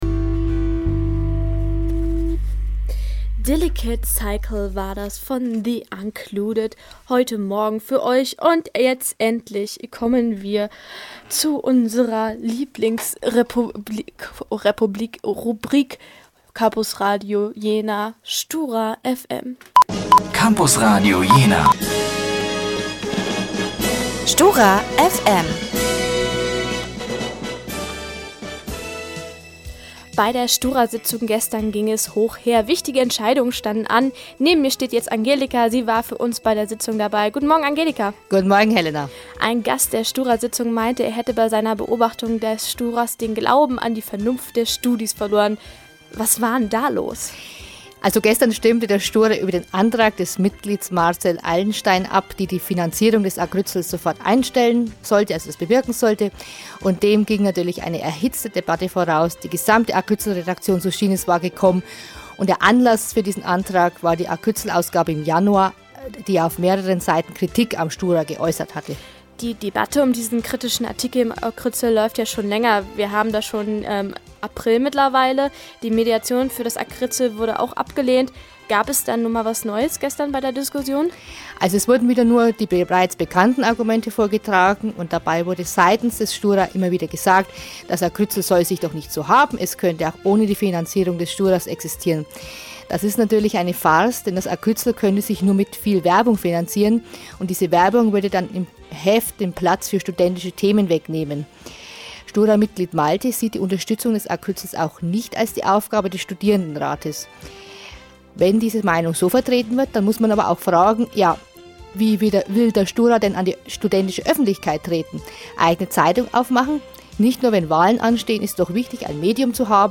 Kollegengespraech_10terApril2013